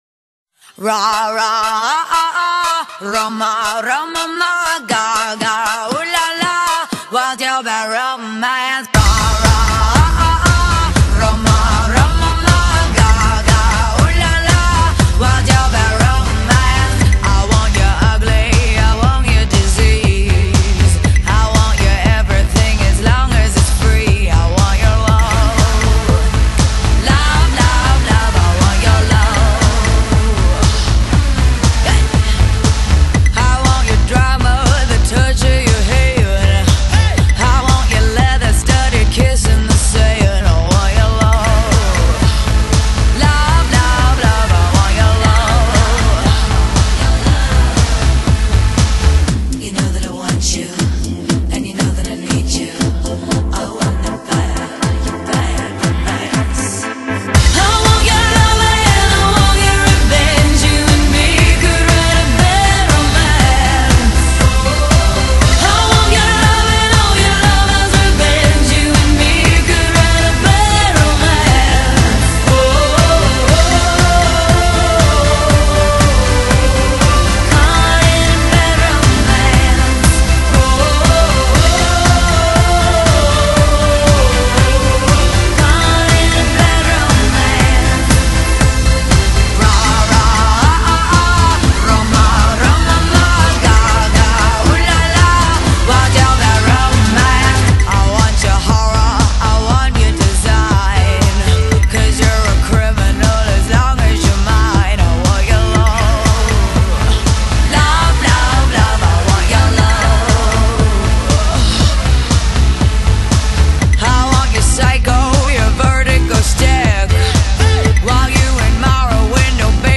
Pop, Soul, Hip Hop, RnB, Pop Rock